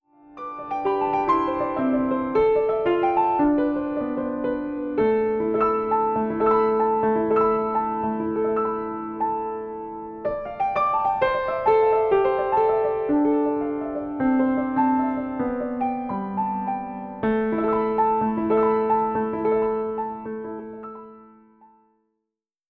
Lyssningsexempel piano